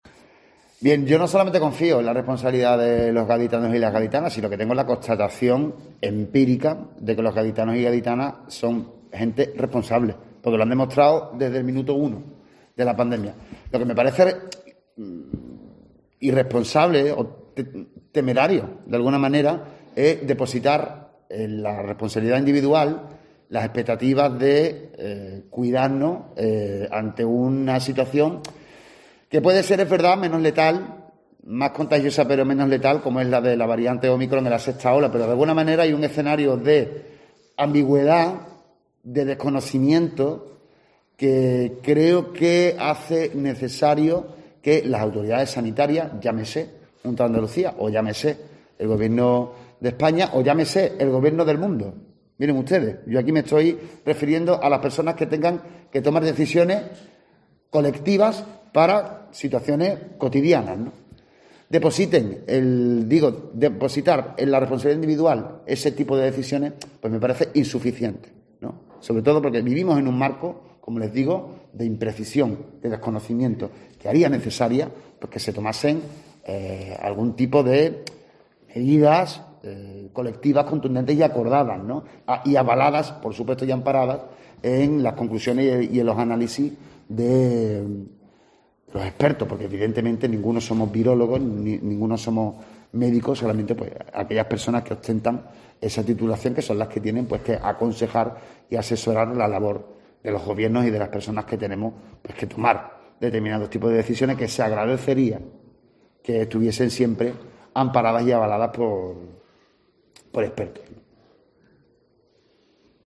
José Marías González, alcalde de Cádiz, sobre el COVID